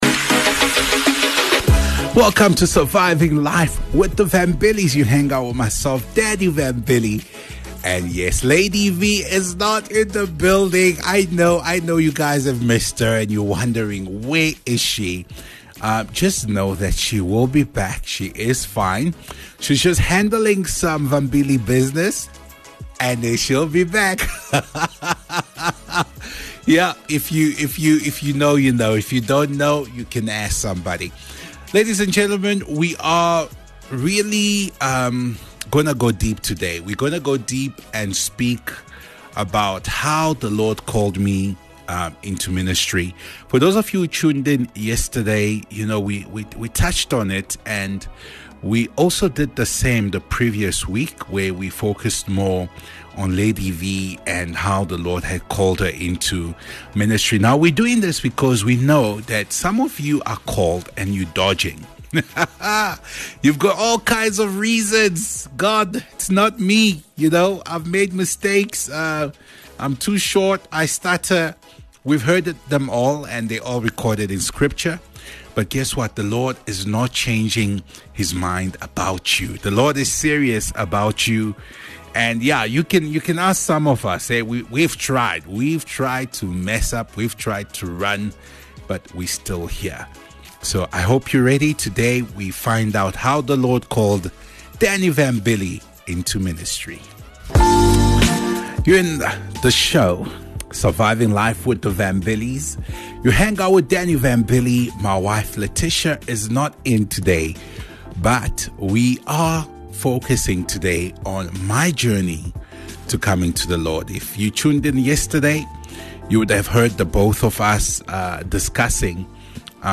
Live Show 42 MIN Download